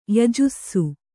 ♪ yajussu